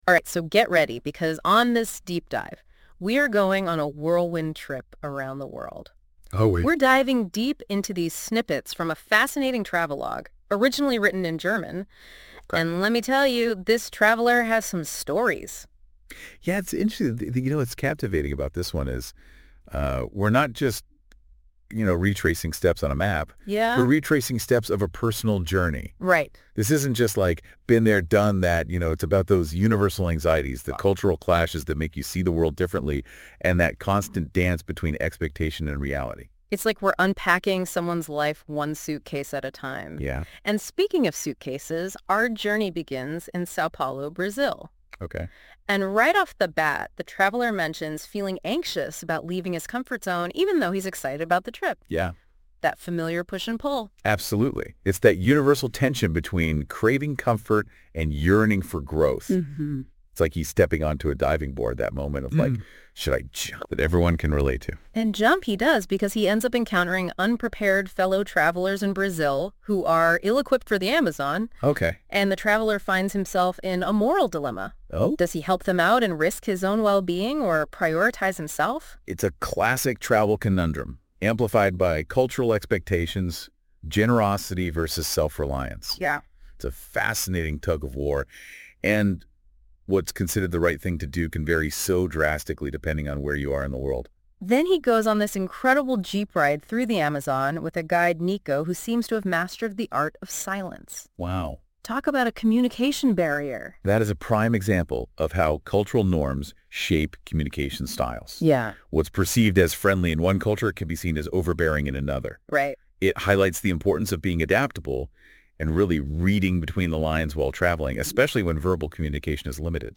Und von der KI hab ich das Buch auch schon mal besprechen lassen. Ich finde, da ist ein ganz spannender Podcast draus geworden.
KI_Podcast_WieichmitSteinenimRucksackumdieWeltrannte.mp3